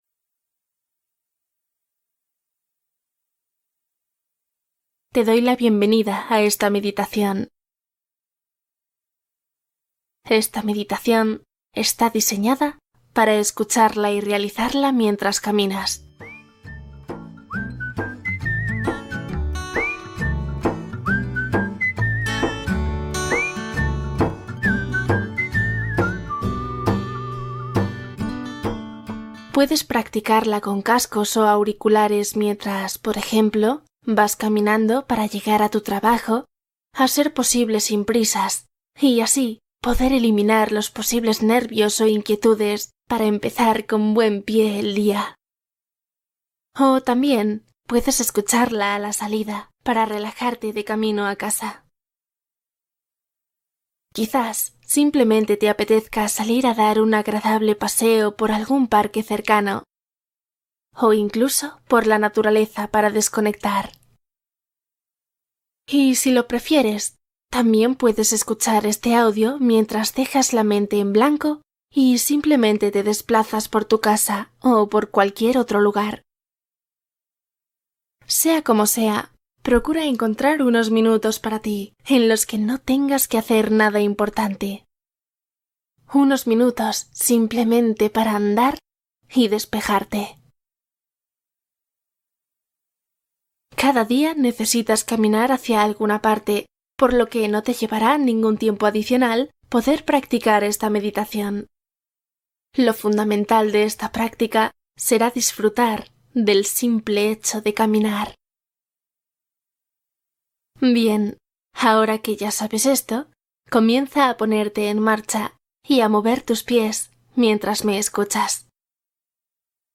Meditación caminando de 10 minutos para presencia total